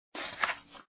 1 channel
768_s01_paper.wav.mp3